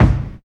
134 KICK.wav